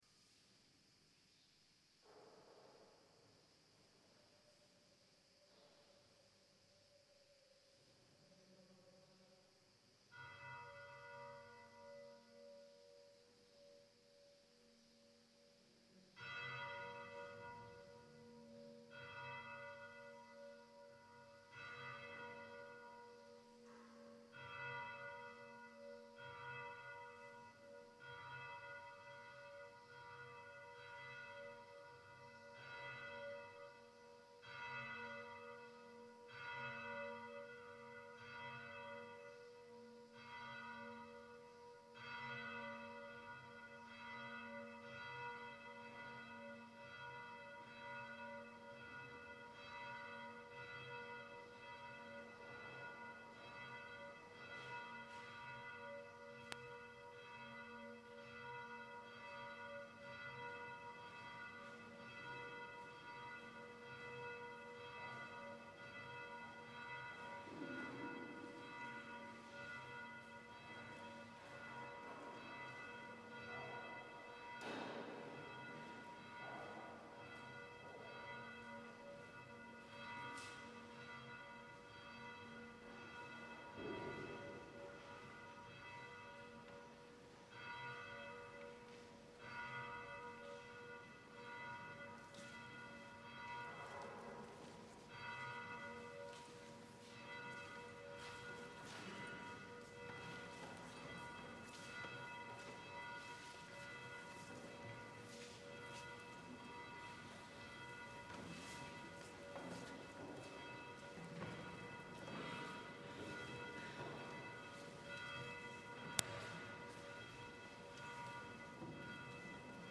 Gregorian
Chanting Monk